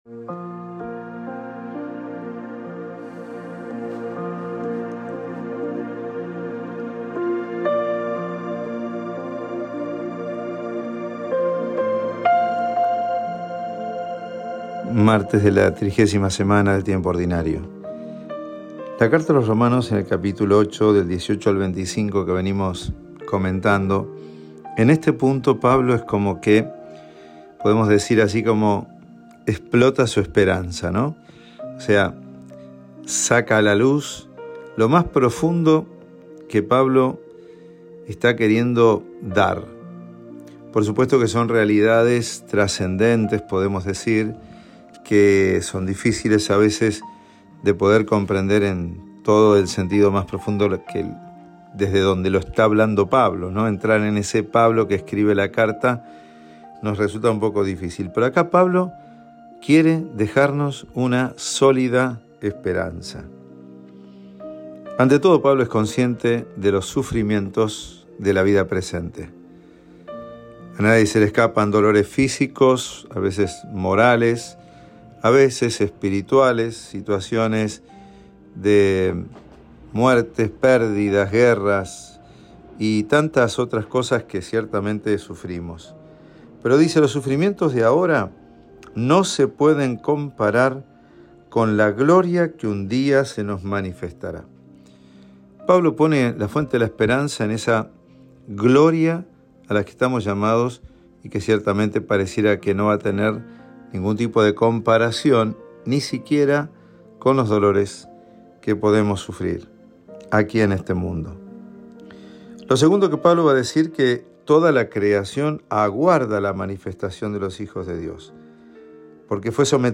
Homilía